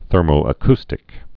(thûrmō-ə-kstĭk)